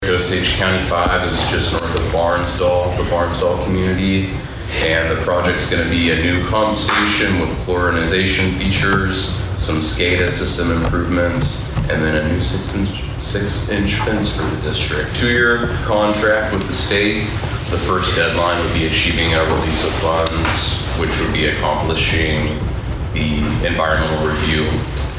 The Board of Osage County Commissioners convened for a regularly scheduled meeting at the fairgrounds on Monday morning. At that meeting, a contract was signed with the Oklahoma Department of Commerce in the amount of more than $136,000 to make improvements to the rural water district number five treatment plant.